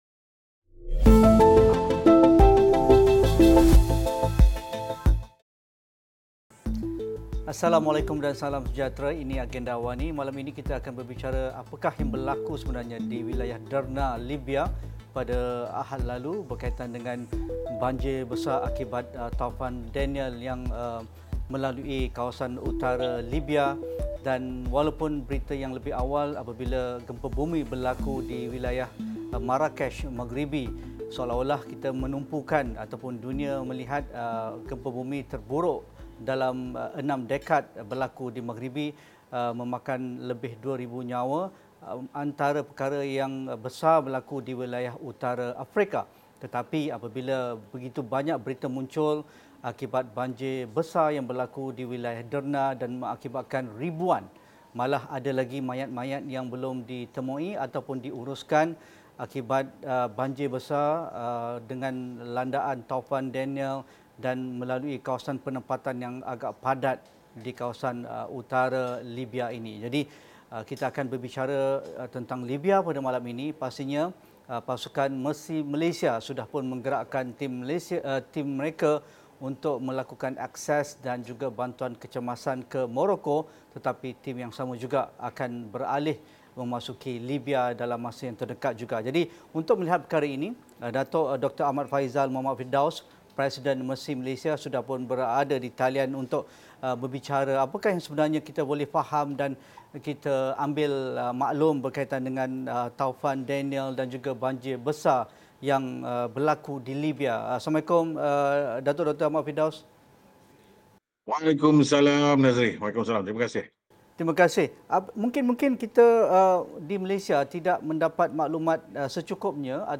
Apa bantuan kecemasan dan keperluan mendesak bagi mangsa bencana banjir di Derna, Libya? Koordinasi dan penyelarasan bantuan daripada pihak dalam dan luar negara, bagaimana ia dilakukan? Diskusi 9 malam